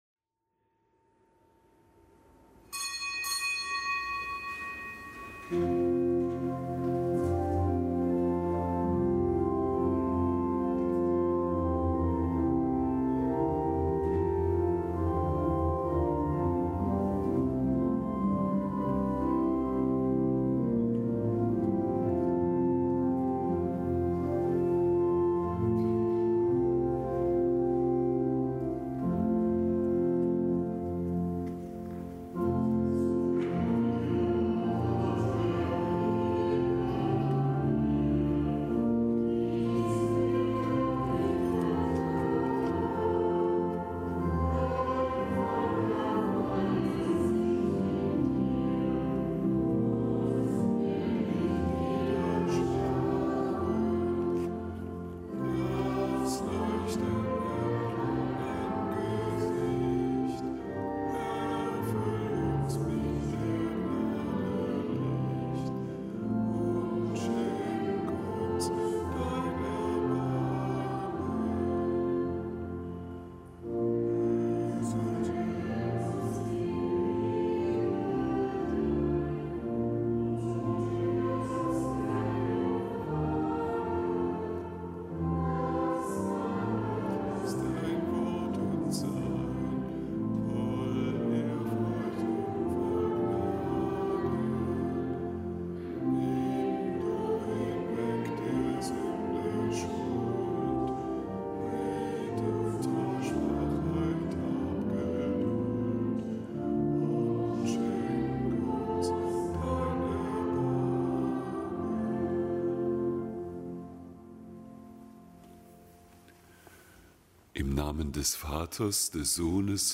Kapitelsmesse aus dem Kölner Dom am Montag der dreißigsten Woche im Jahreskreis. Zelebrant: Weihbischof Ansgar Puff.